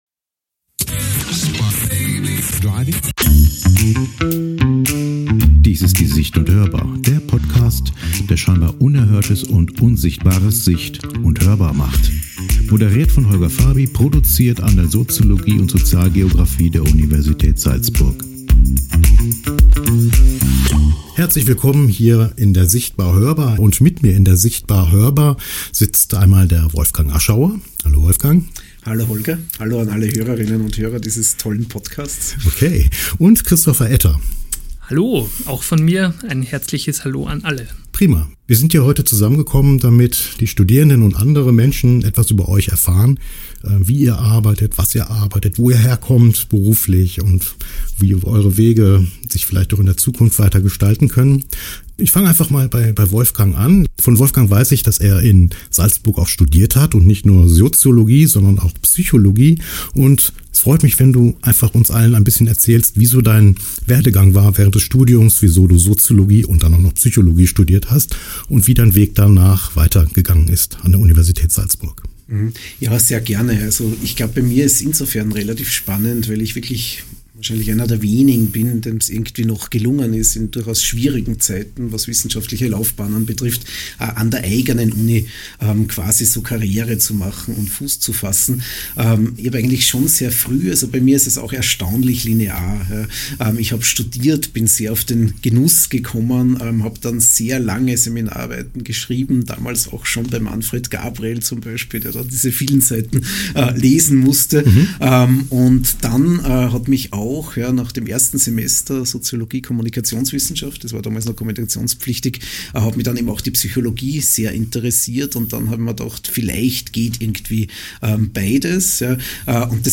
Ein Gespräch über Wissenschaft, das unter die Oberfläche geht: